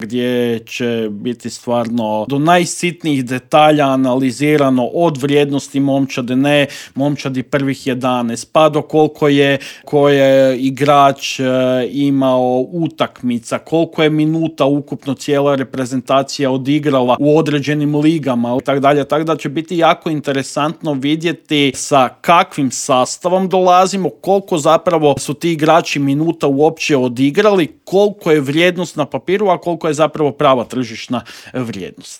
U intervjuu Media Servisa razgovarali smo o "maloj tvornici medaljaša"